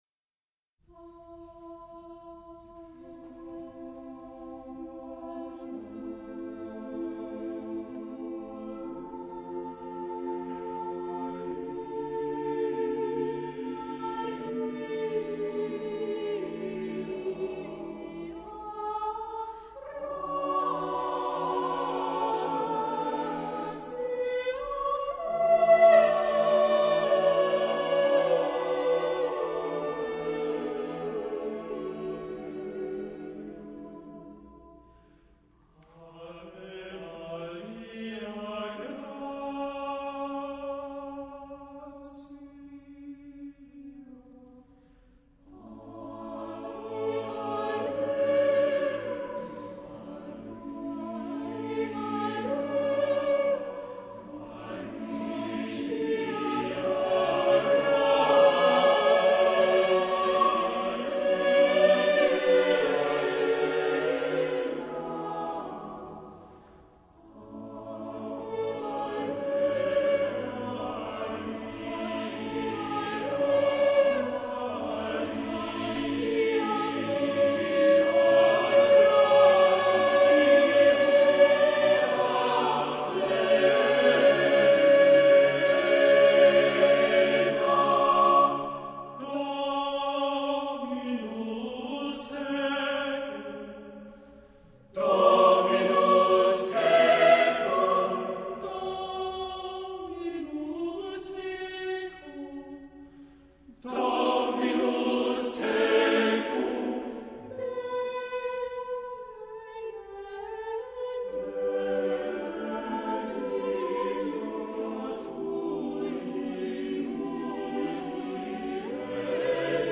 SATB
Accompaniment:      Reduction
Music Category:      Choral